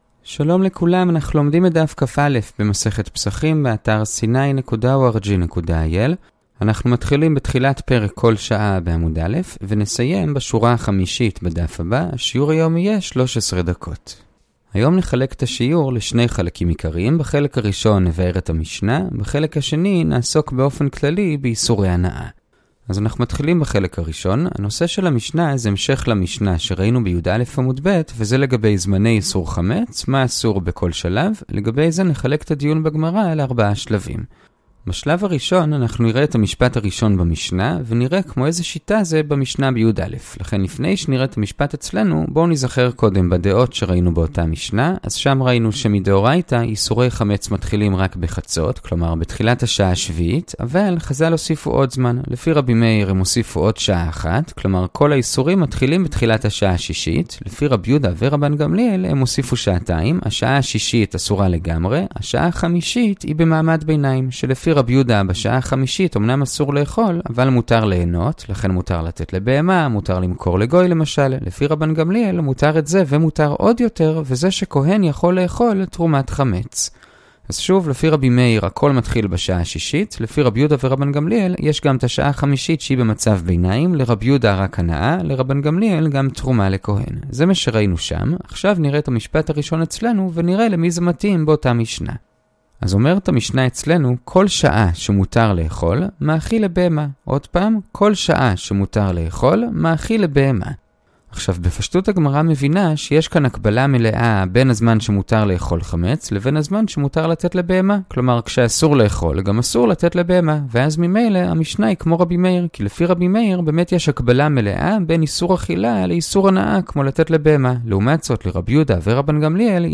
הדף היומי - פסחים כא - הדף היומי ב15 דקות - שיעורי דף יומי קצרים בגמרא